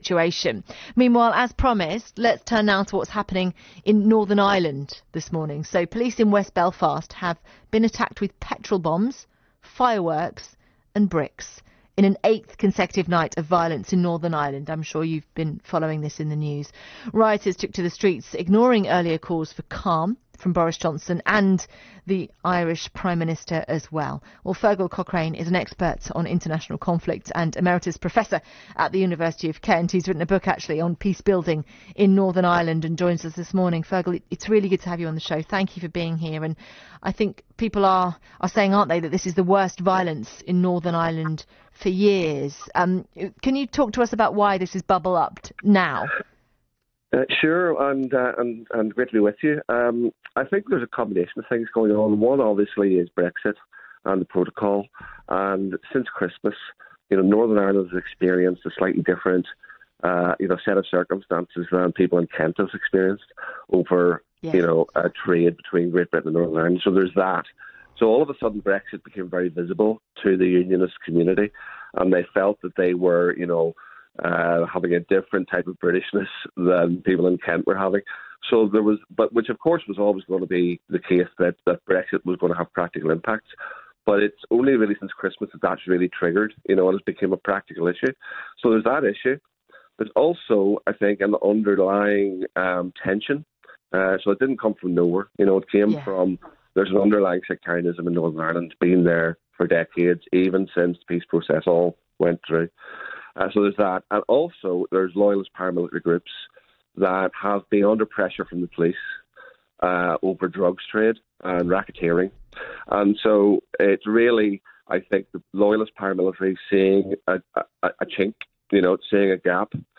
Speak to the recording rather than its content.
Interview on BBC Radio Kent on 9 April 2021 relating to the riots in Belfast that were taking place that week. click the download button to listen